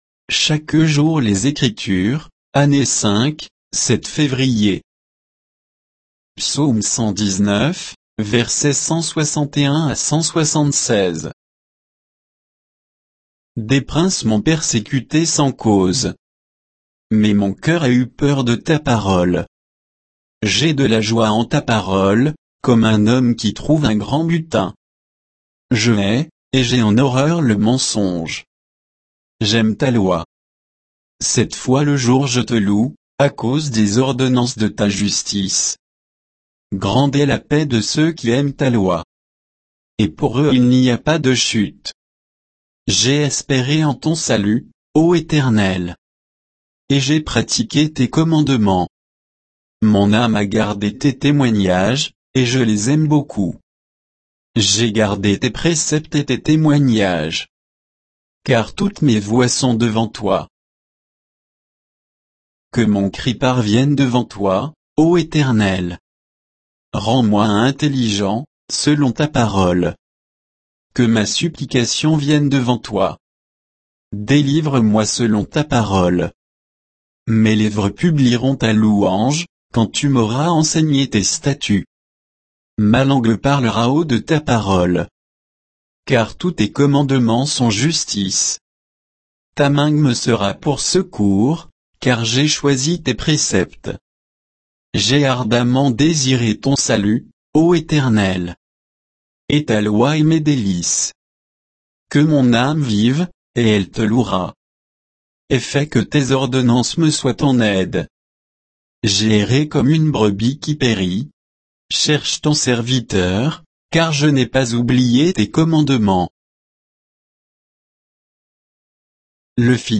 Méditation quoditienne de Chaque jour les Écritures sur Psaume 119